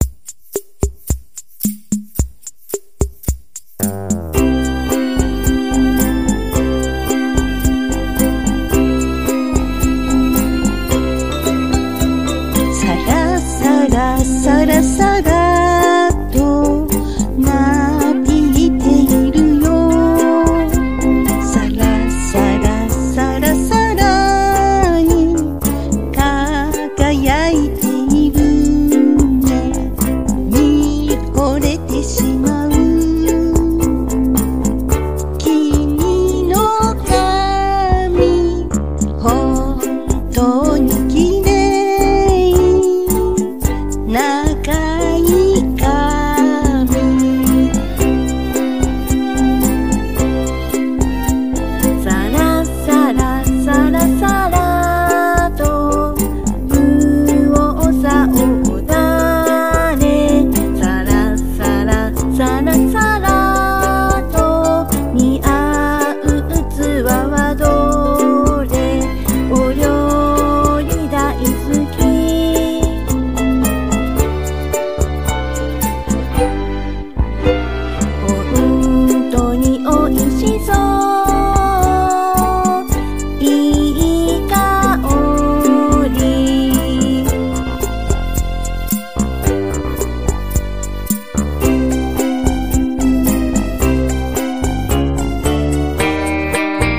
ひとつ音符を除きました。